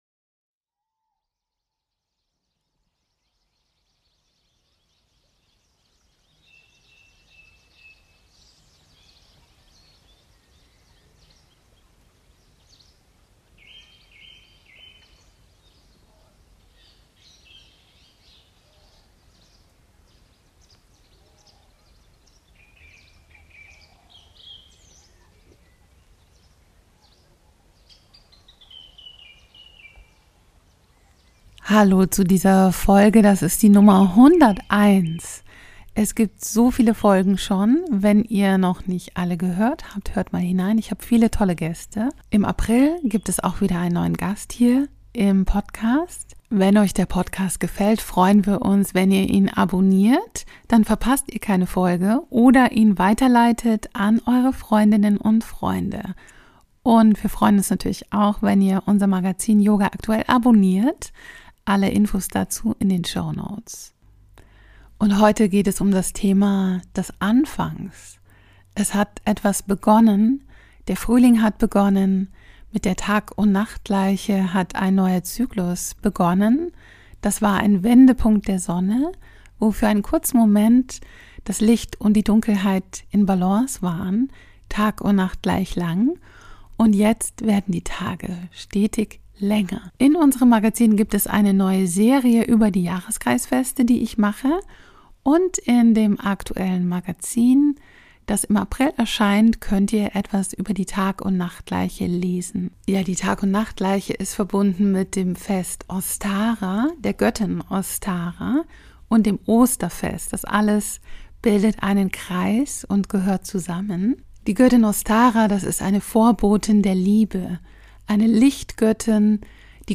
Impulse für den Frühlingsbeginn, Ostara und das Anfangen. Und eine Meditation für den Anfang.